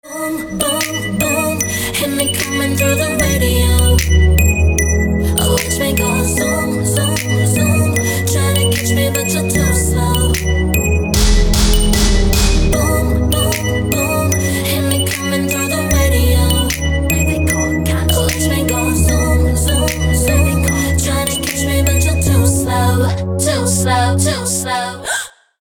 • Качество: 320, Stereo
поп
женский вокал
спокойные
колокольчики